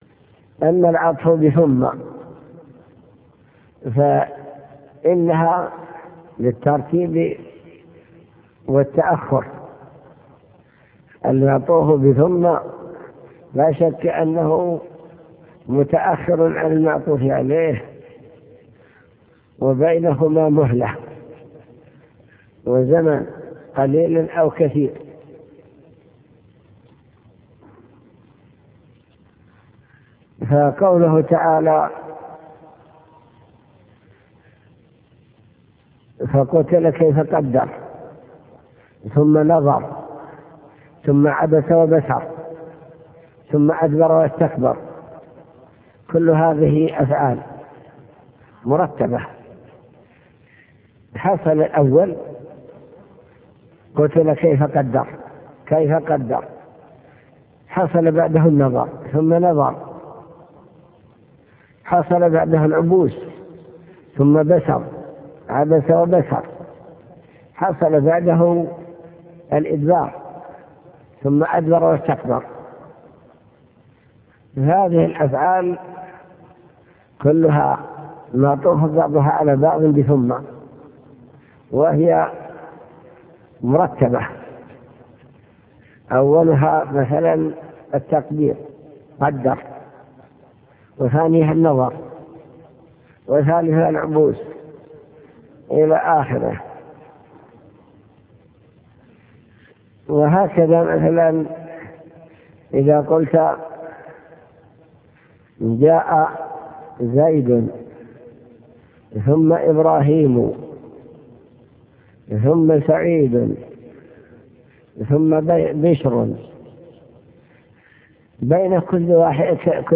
المكتبة الصوتية  تسجيلات - كتب  شرح كتاب الآجرومية العطف وأقسامه حروف العطف